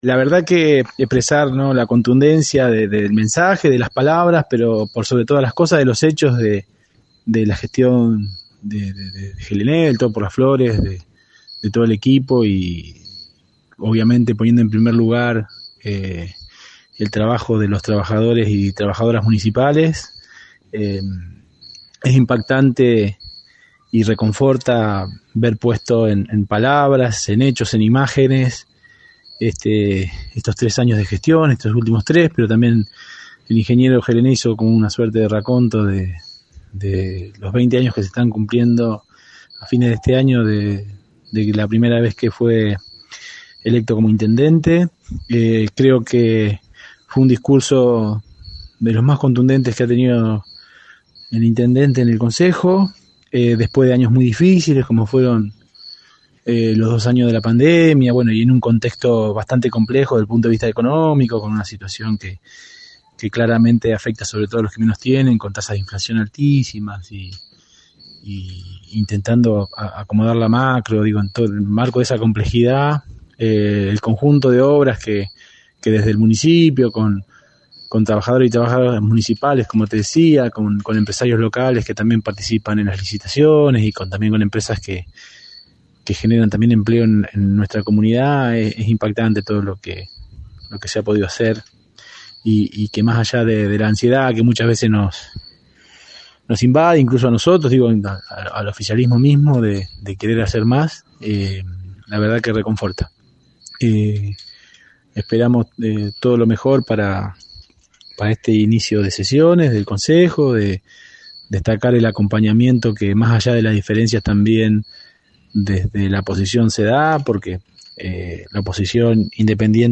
Las voces de los concejales de distintos bloques del Concejo Deliberante:
Concejal por el oficialismo Leo Municoy: “Va a ser un año cruzado por la gestión electoral donde se pondrá en juego si Las Flores debe seguir o no por este camino…”